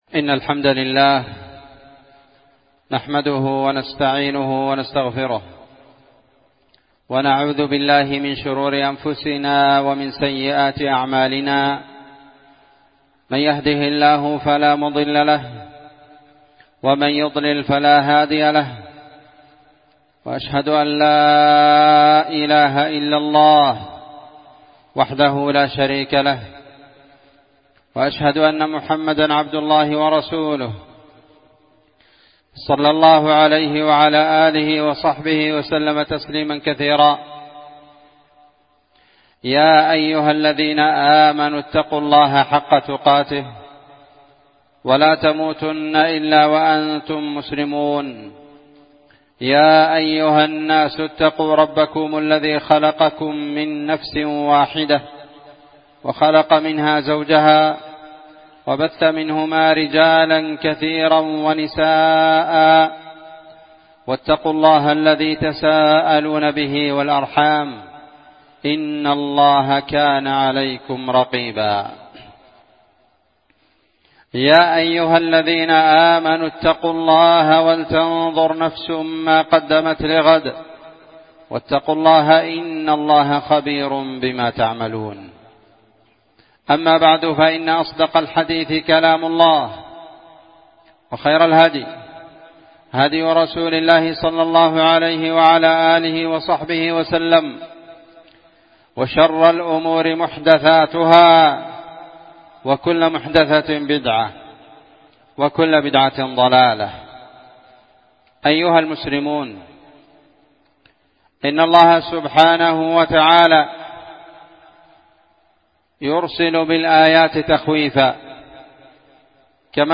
خطبة جمعة قيمة
إندونيسيا- جزيرة سولاويسي- مدينة بوني- قرية تيرونج- مسجد الإخلاص